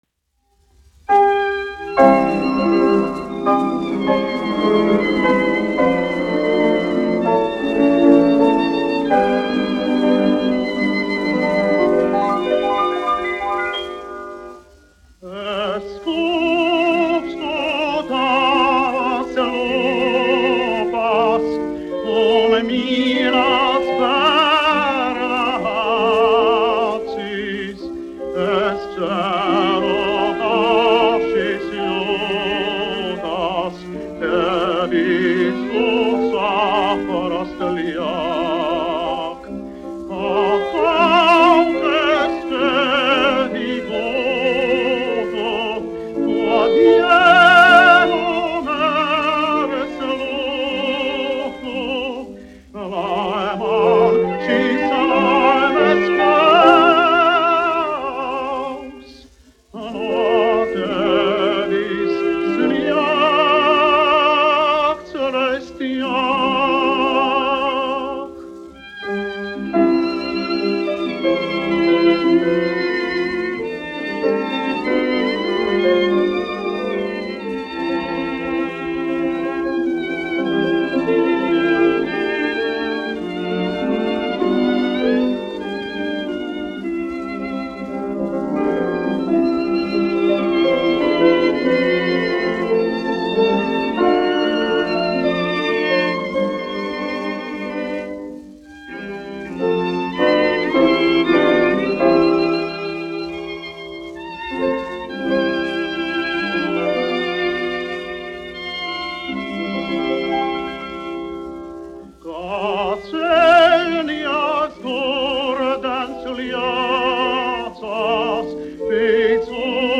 1 skpl. : analogs, 78 apgr/min, mono ; 25 cm
Dziesmas (augsta balss) ar instrumentālu ansambli
Latvijas vēsturiskie šellaka skaņuplašu ieraksti (Kolekcija)